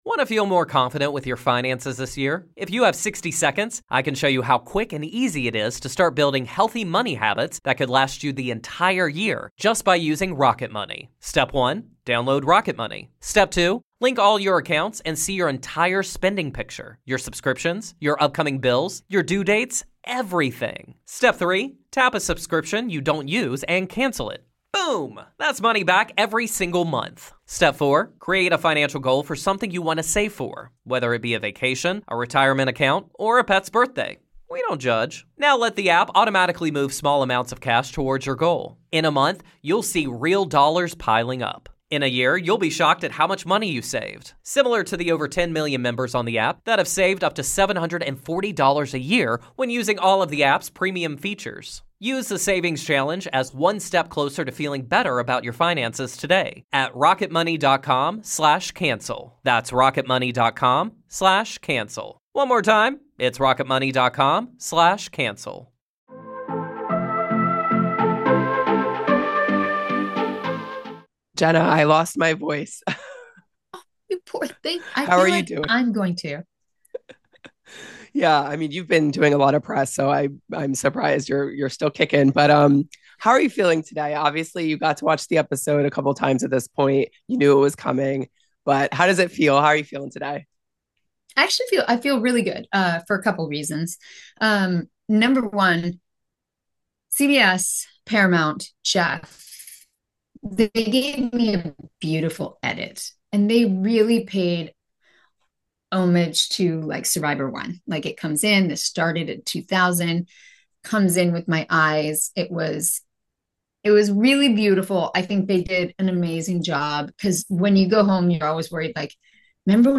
Survivor 50 Exit Interview: First Player Out
Survivor legend Jenna Lewis-Dougherty joins The Exclusive for an exit interview following the Survivor 50 premiere.